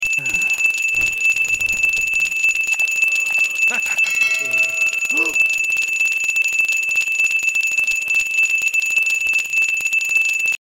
bell spam LOUD